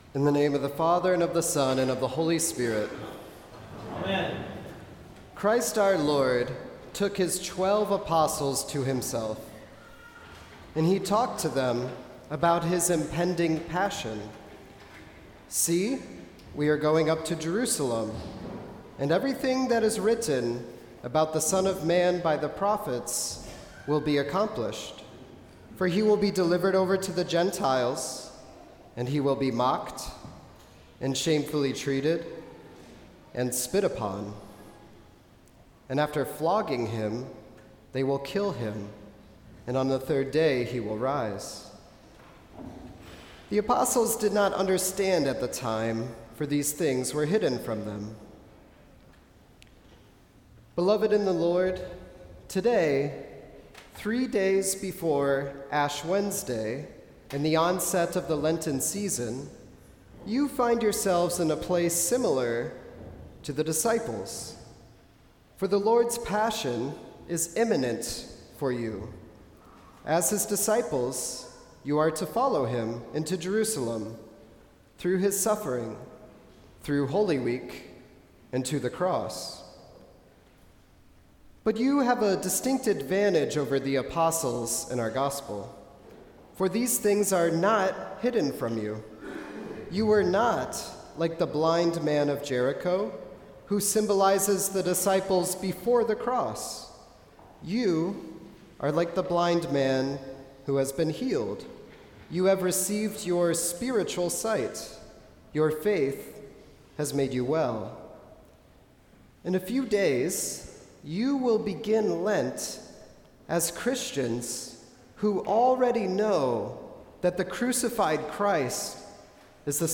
Sermon for Quinquagesima